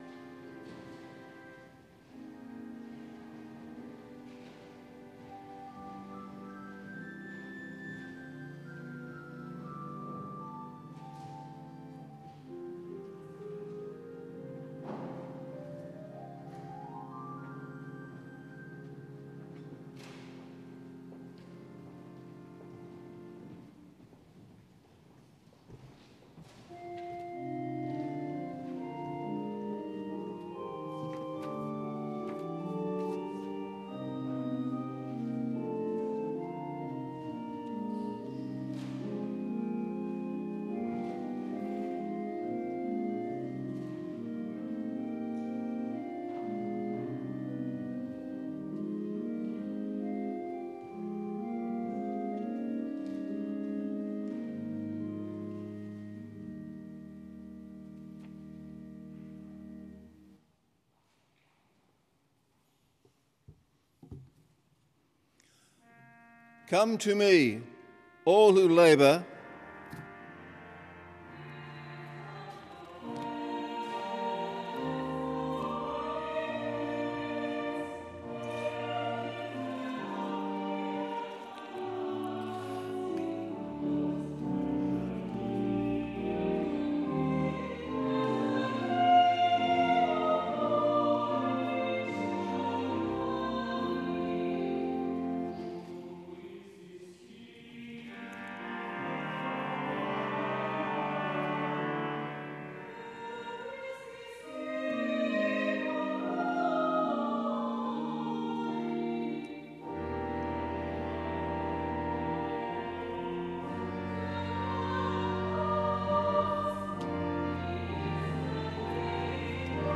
Full Service Audio
The Scots’ Church Melbourne 11am Service 10th of January 2021